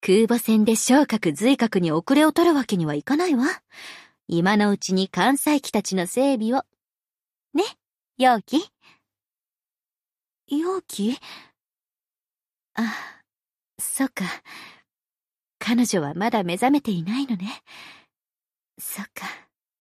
Ship Voice Lexington Idle.mp3